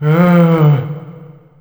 c_zombim2_atk2.wav